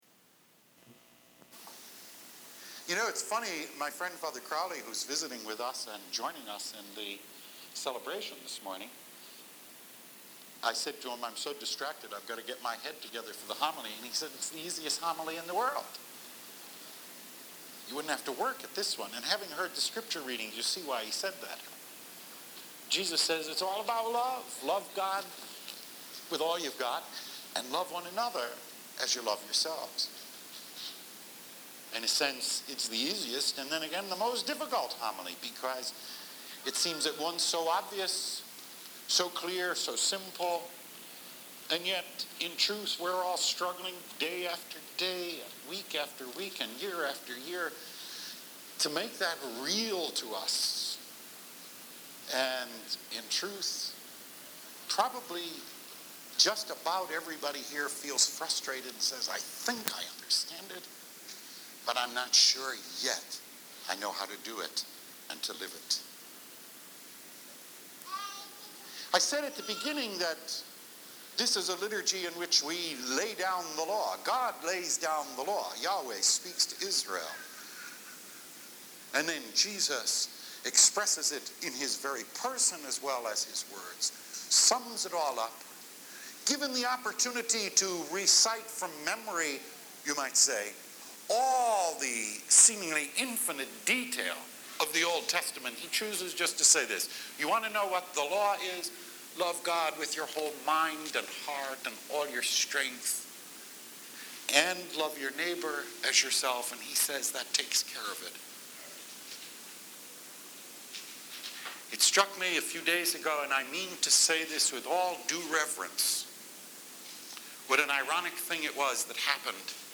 God is Love – Weekly Homilies
Originally delivered on October 20, 1991